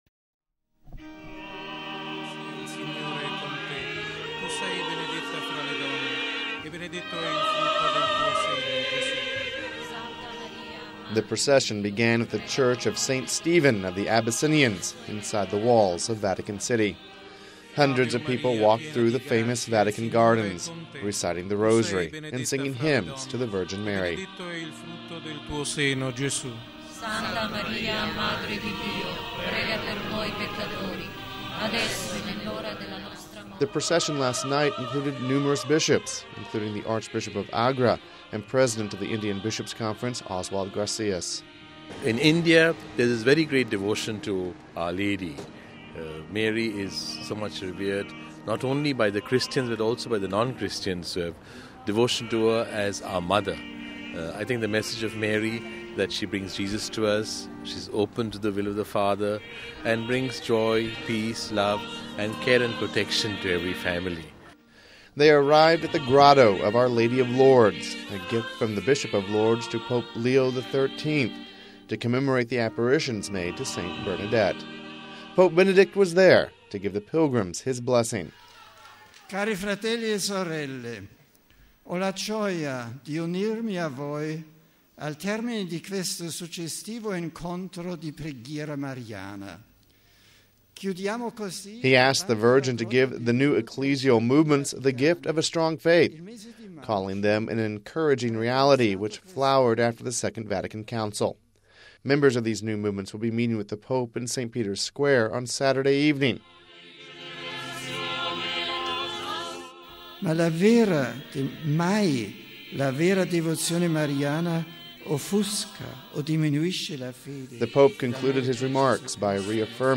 reports...